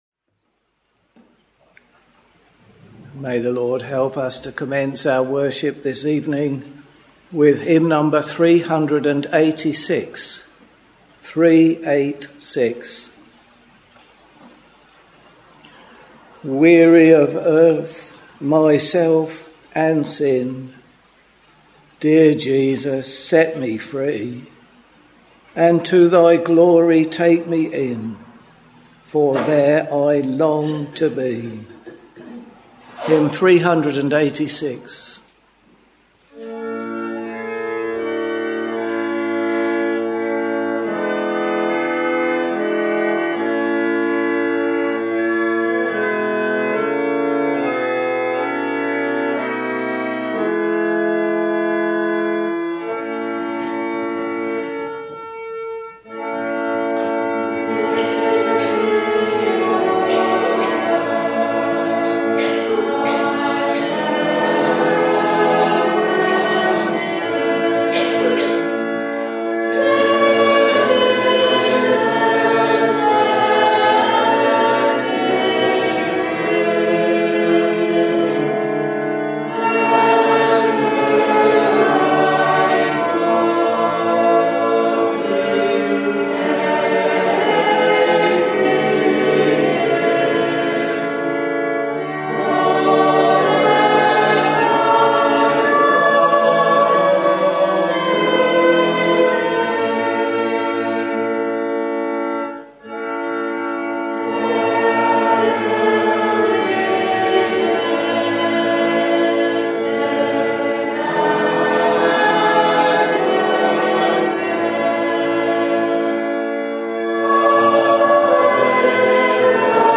We are very pleased for you to listen to the live or archived services if you are not able to assemble for public worship in your local church or chapel.